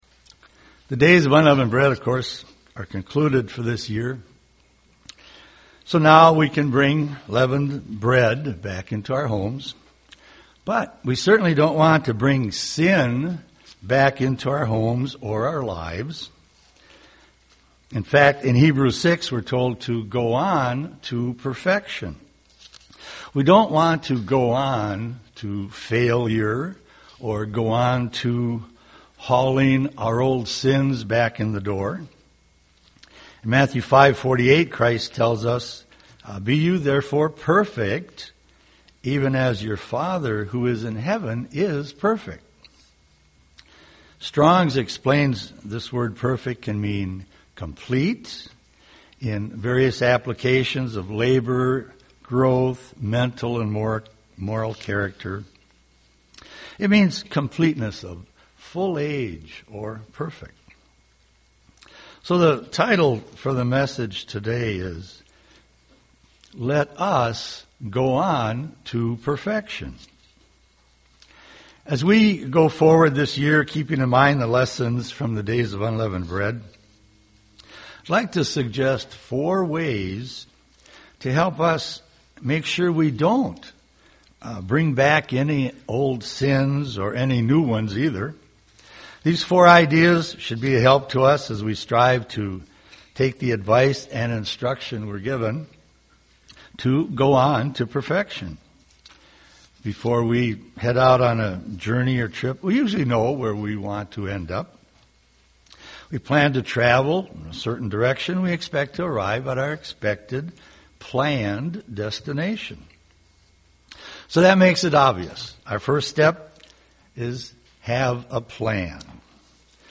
UCG Sermon Hebrews 6 perfection Studying the bible?